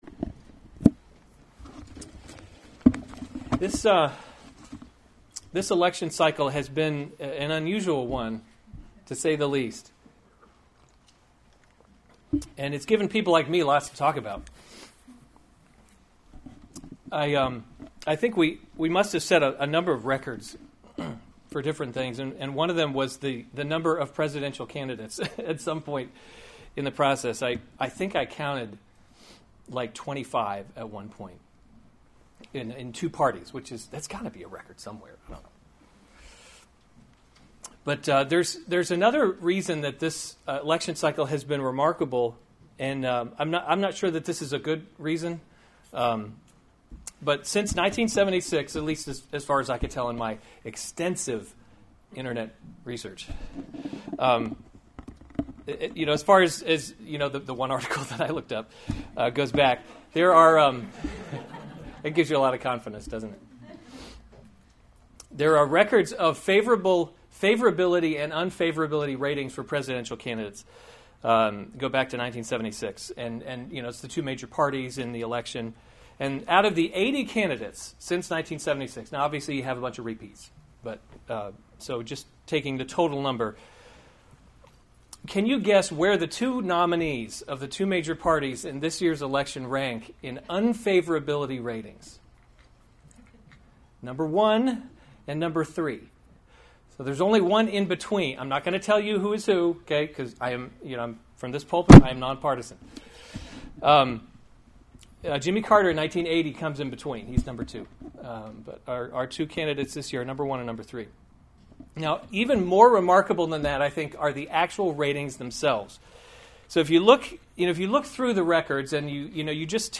August 27, 2016 Psalms – Summer Series series Weekly Sunday Service Save/Download this sermon Psalm 110 Other sermons from Psalm Sit at My Right Hand A Psalm of David. 110:1 The […]